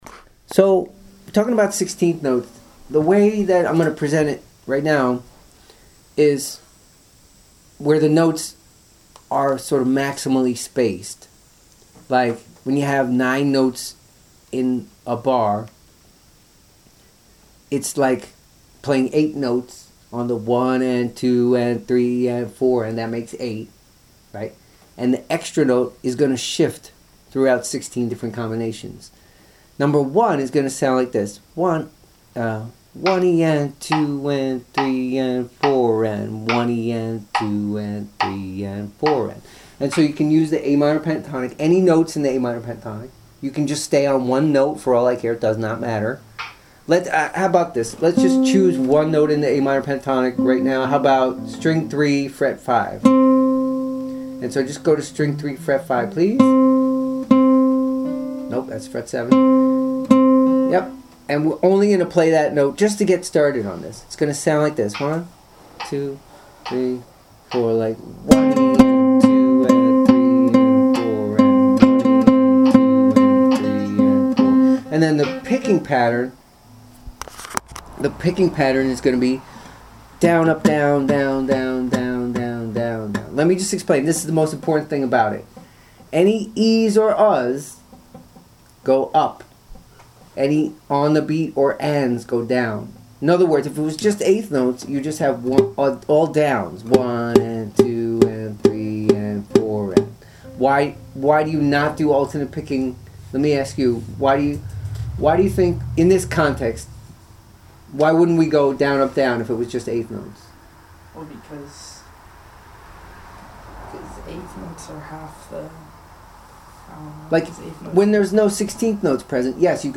Rhythm
16th-notes-maximally-spaced-1.mp3